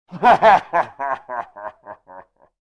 chortle.wav